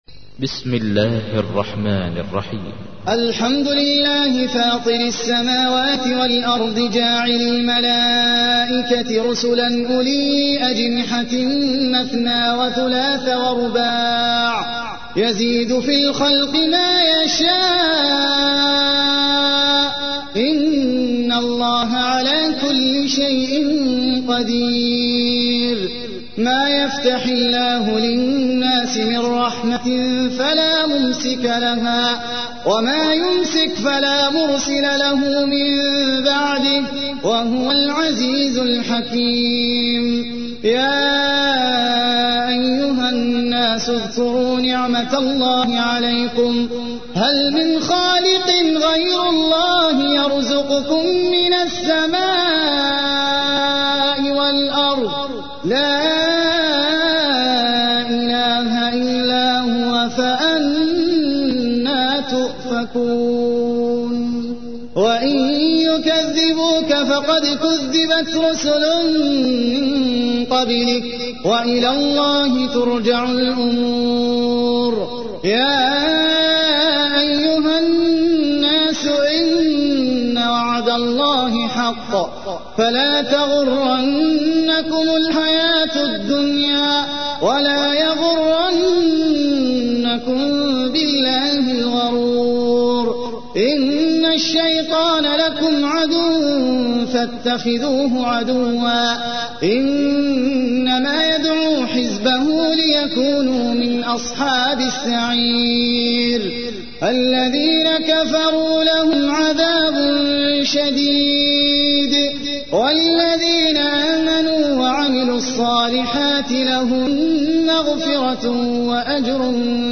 تحميل : 35. سورة فاطر / القارئ احمد العجمي / القرآن الكريم / موقع يا حسين